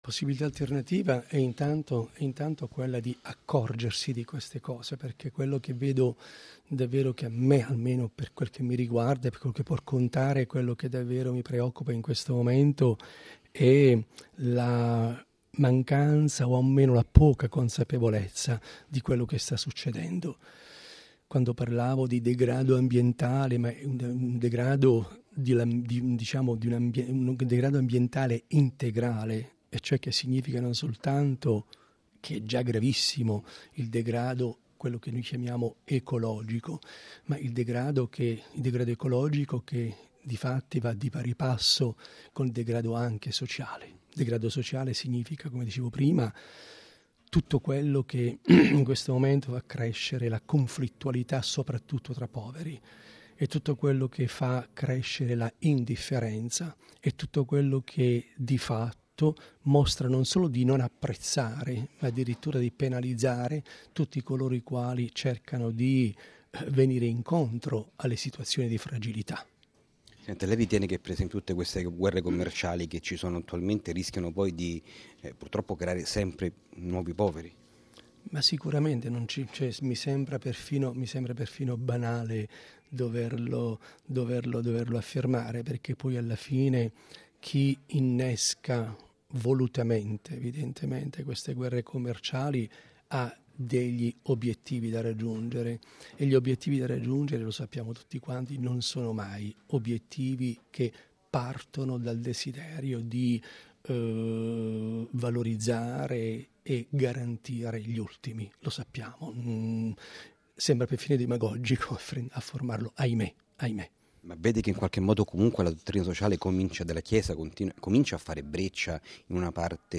Vatican Radio Interview with His Excellency Mgr. Nunzio Galantino, President of APSA, 7th June 2019